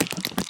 Звуки пауков
Все звуки оригинальные и взяты прямиком из игры.
Передвижение/Шаги №3
SpiderStep3.mp3